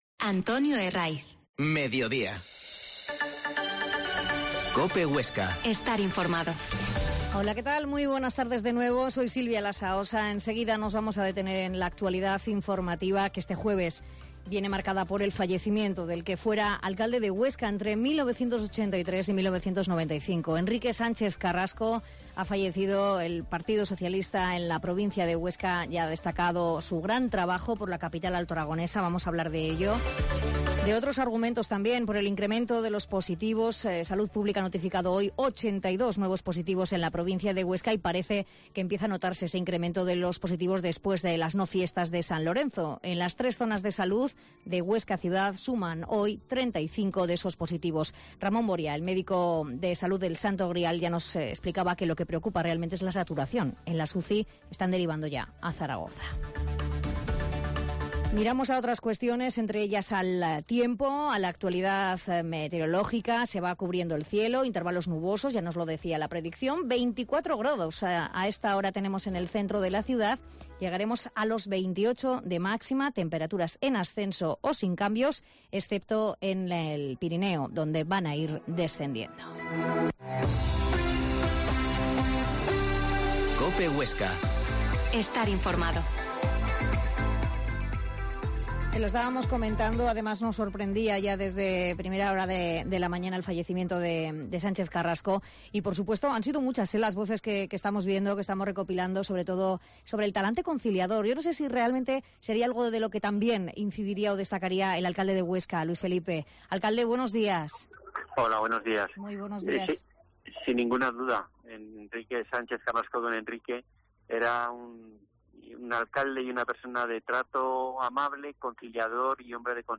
Mediodia en COPE Huesca 13.20h Entrevista al alcalde Luis Felipe y al duo DR Kowalsky